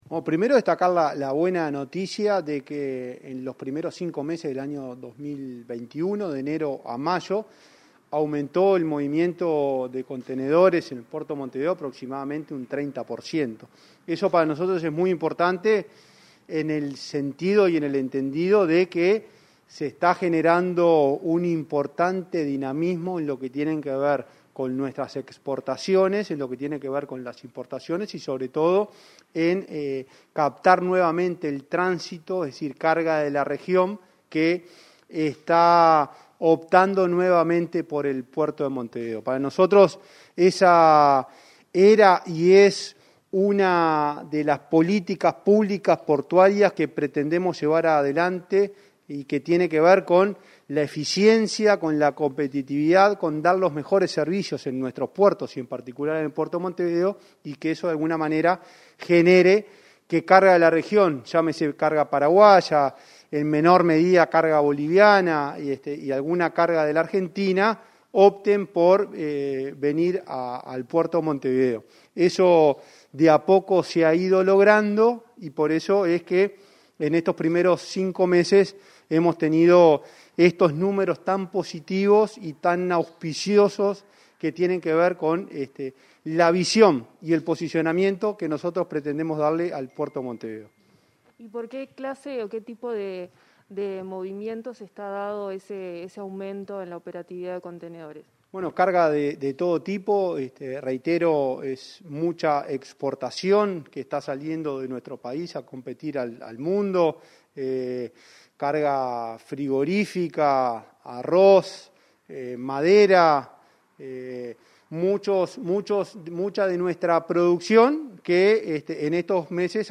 Palabras de Juan Curbelo, presidente de la ANP